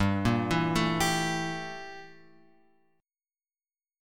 Gm6add9 chord {3 1 2 2 x 3} chord